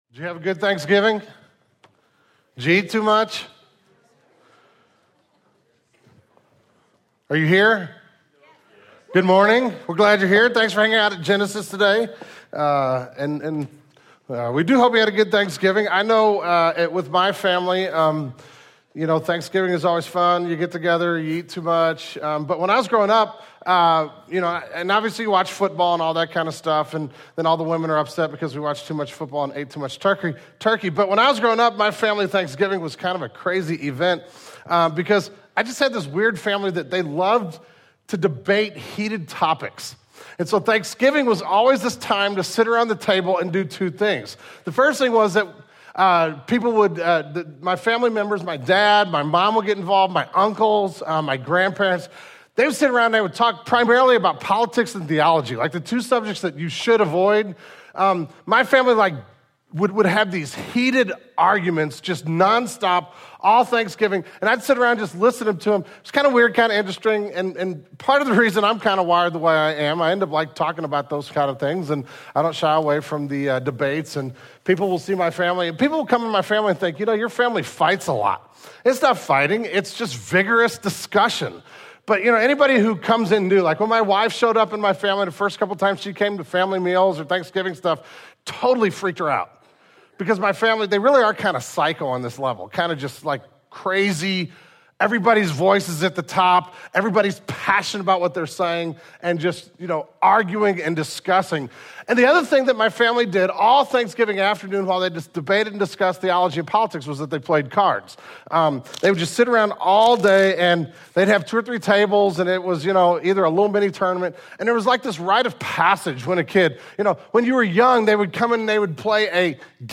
The Sermon Audio archive of Genesis Church - Page 76.